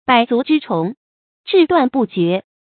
bǎi zú zhī chóng，zhì duàn bù jué
百足之虫，至断不蹶发音